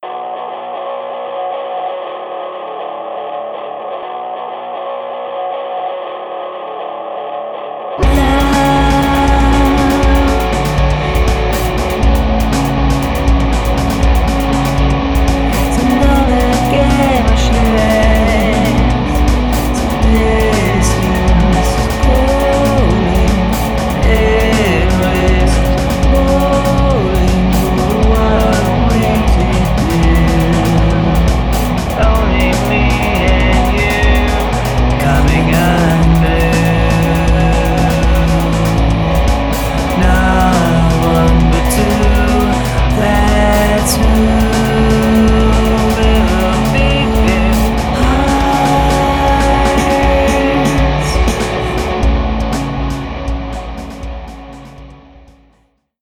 independent rock song